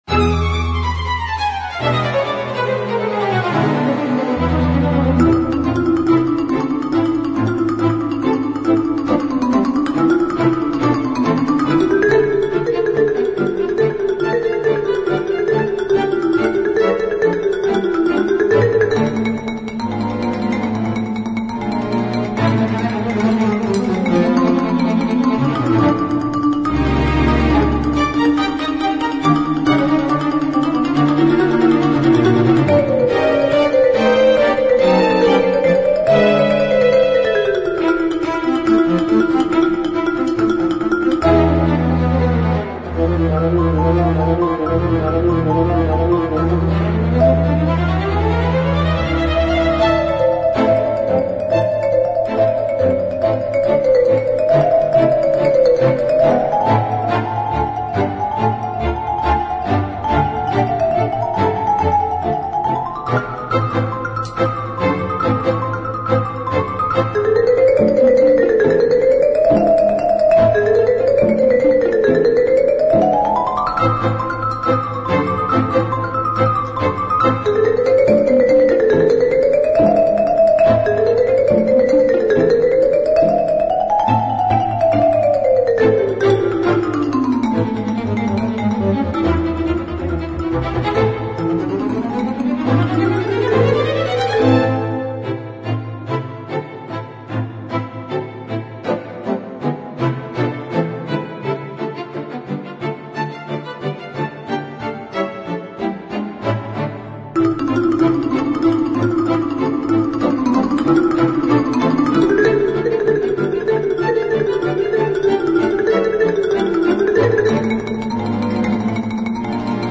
Marimba